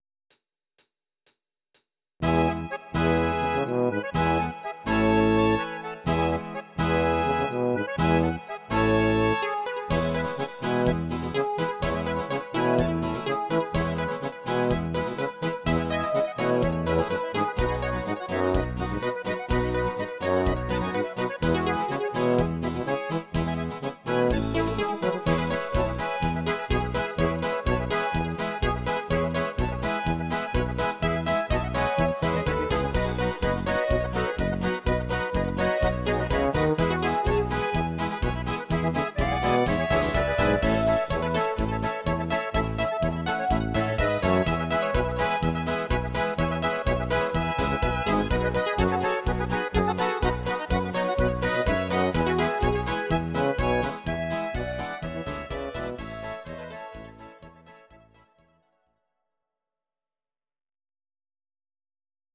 These are MP3 versions of our MIDI file catalogue.
Please note: no vocals and no karaoke included.
Oberkrainer Sound